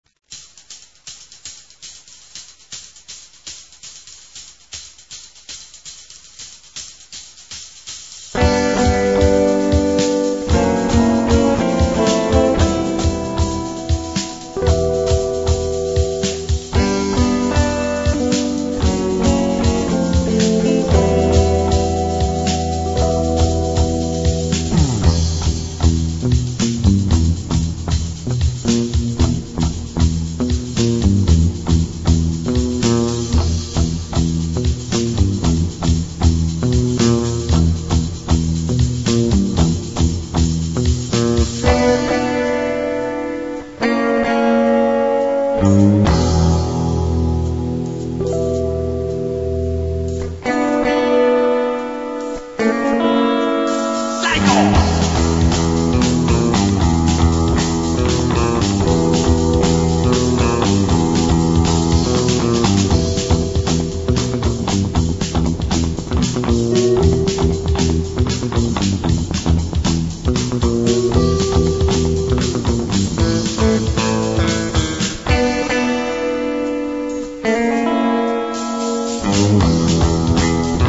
Сайко-инструментал